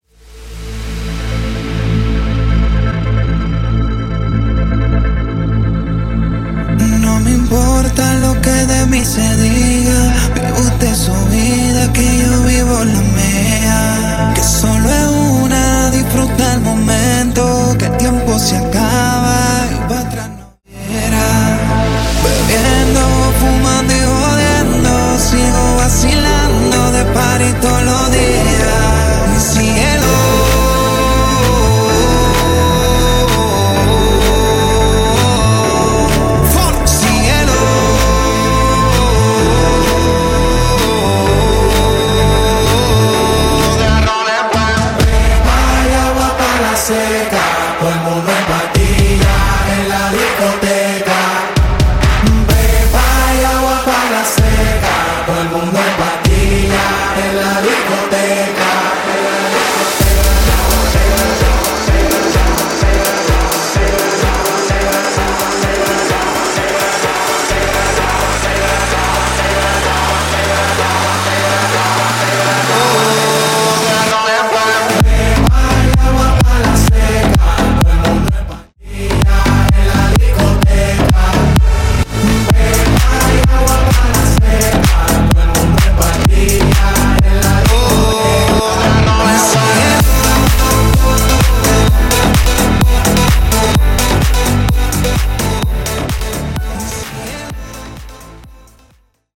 Genres: R & B , RE-DRUM , TOP40
Clean BPM: 105 Time